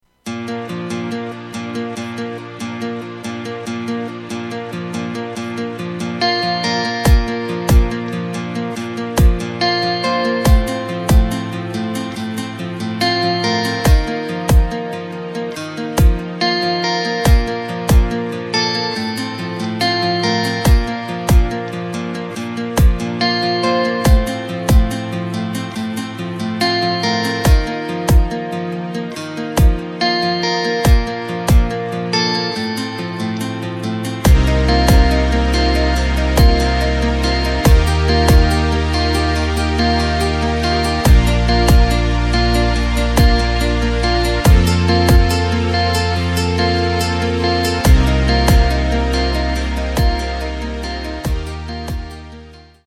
Takt: 4/4 Tempo: 141.20 Tonart: Bb
Hochzeits-Song aus dem Jahr 2018!